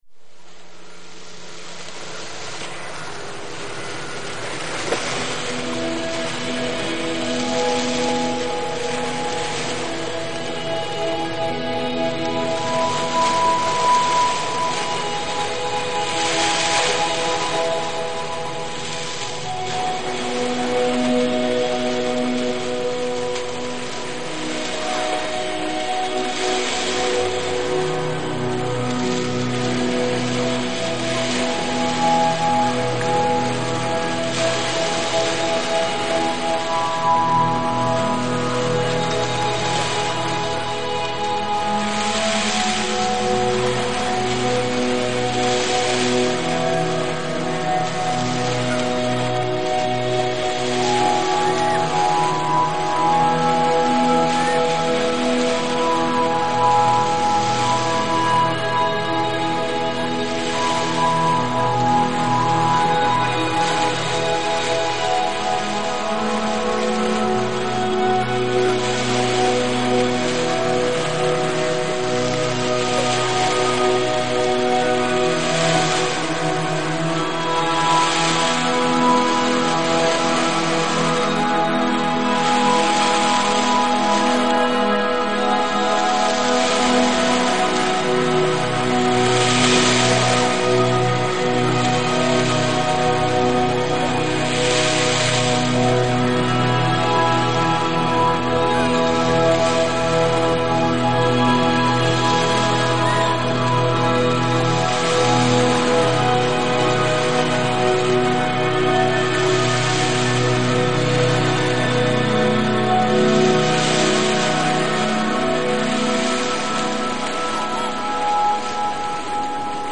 The flute was an addition during editing.